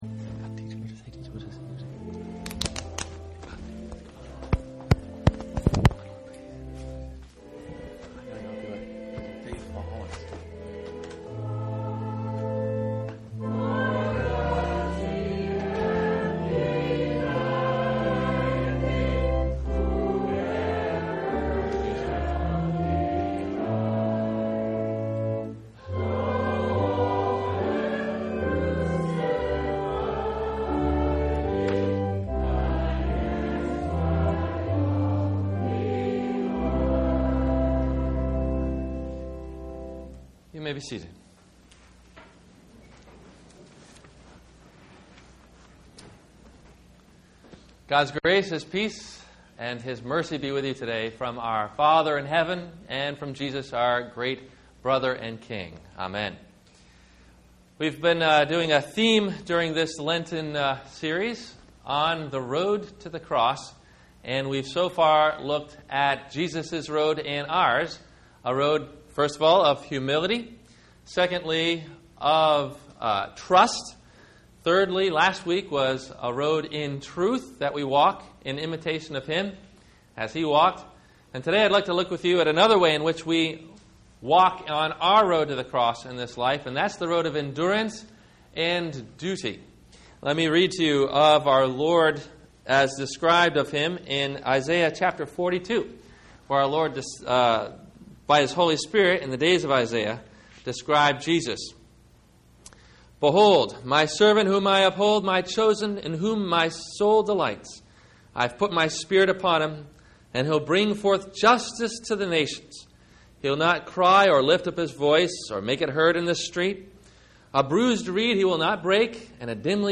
Lent week 3 - Sermon - March 18 2009 - Christ Lutheran Cape Canaveral